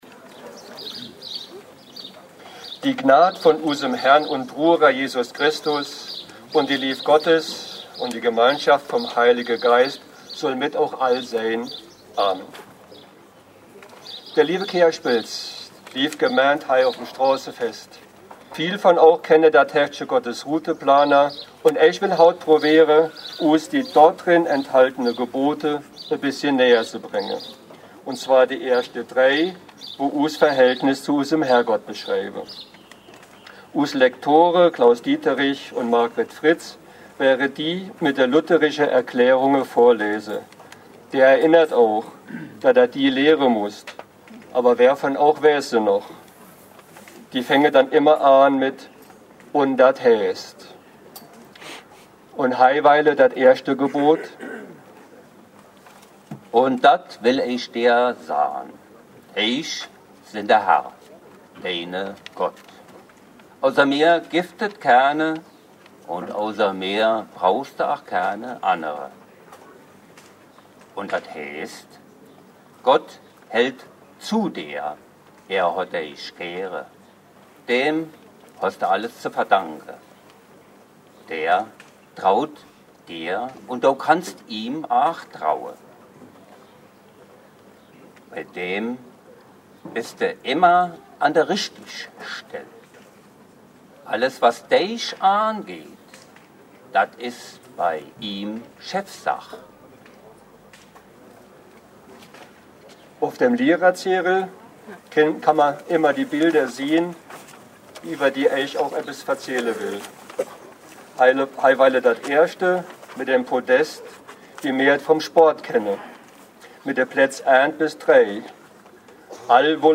Juli 2016 – Predigt im Gottesdienst beim Kleinicher Straßenfest